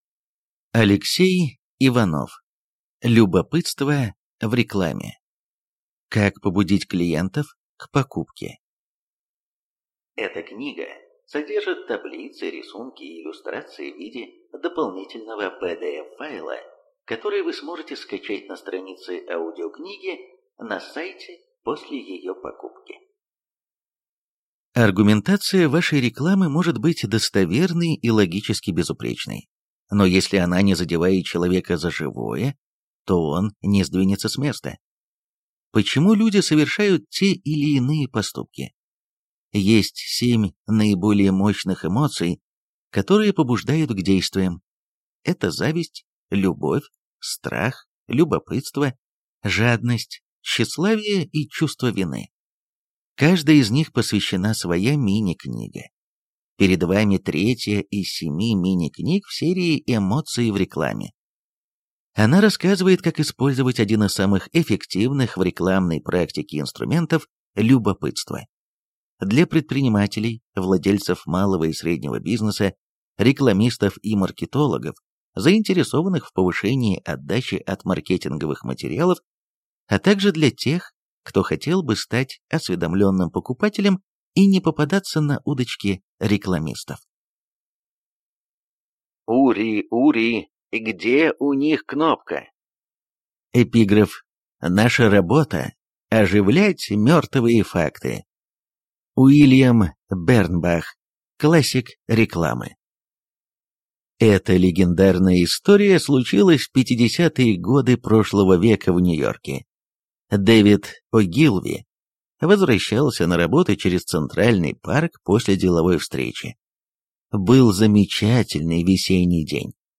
Аудиокнига Любопытство в рекламе. Как побудить клиентов к покупке | Библиотека аудиокниг